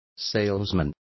Complete with pronunciation of the translation of salesmen.